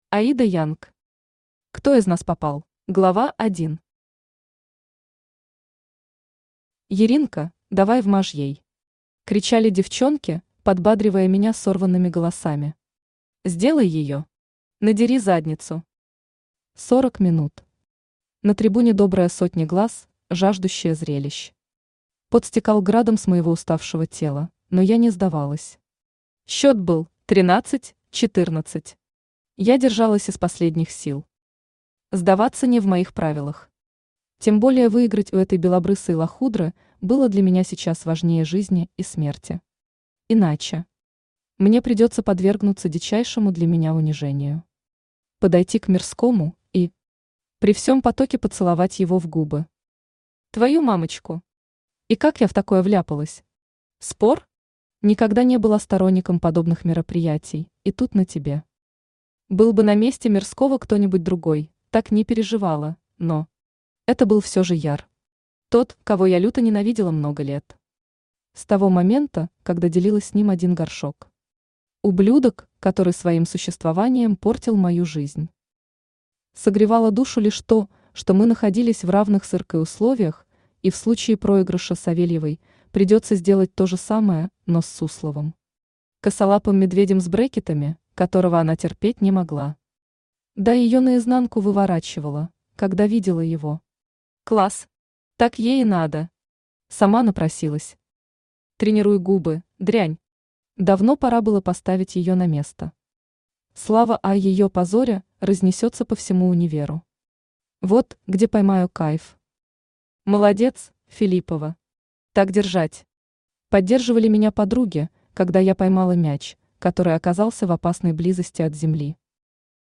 Аудиокнига Кто из нас попал?
Автор Аида Янг Читает аудиокнигу Авточтец ЛитРес.